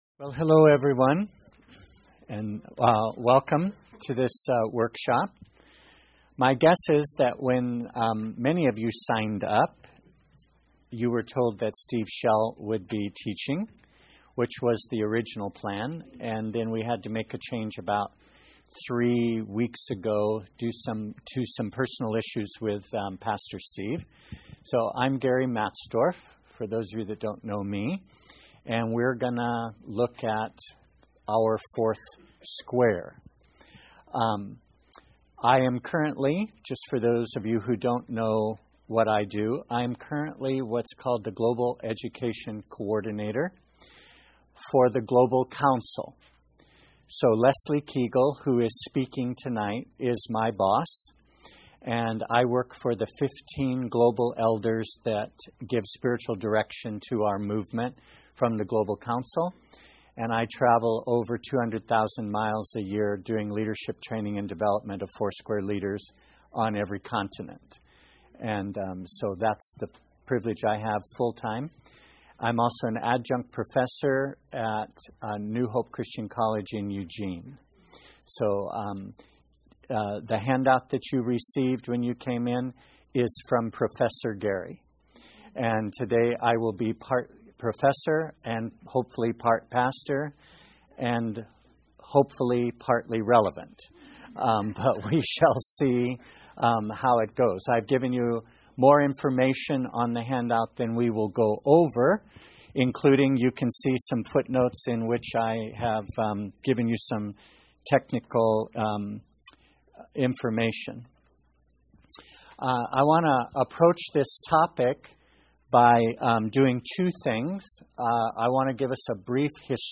Workshop: Our fourth square